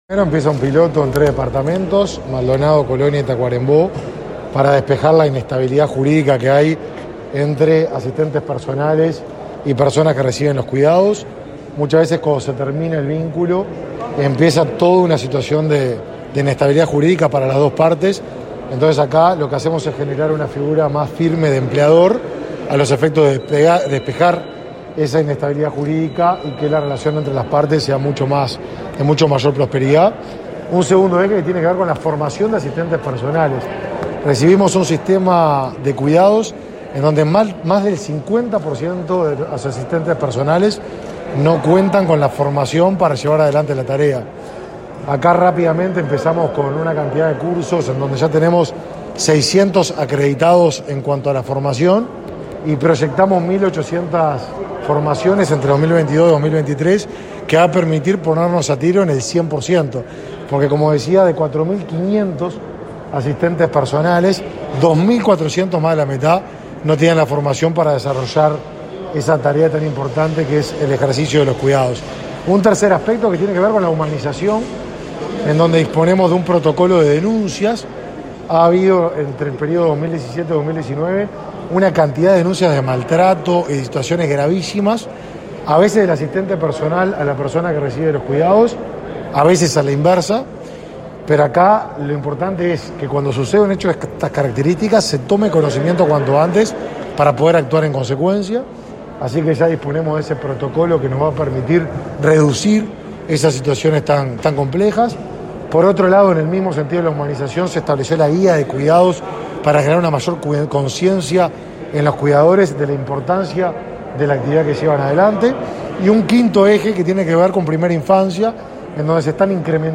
Declaraciones a la prensa del ministro de Desarrollo Social, Martín Lema
Declaraciones a la prensa del ministro de Desarrollo Social, Martín Lema 05/04/2022 Compartir Facebook X Copiar enlace WhatsApp LinkedIn El ministro de Desarrollo Social, Martín Lema, participó este martes 5 en Torre Ejecutiva, del lanzamiento del Mes de los Cuidados y, luego, dialogó con la prensa.